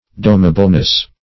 Domableness \Dom"a*ble*ness\, n.